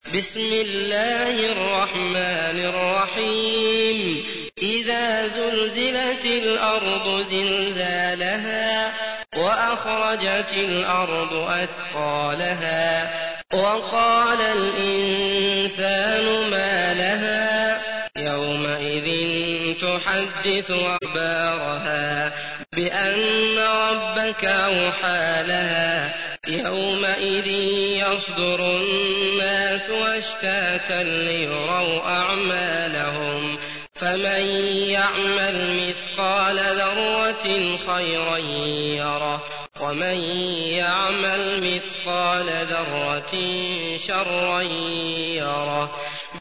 تحميل : 99. سورة الزلزلة / القارئ عبد الله المطرود / القرآن الكريم / موقع يا حسين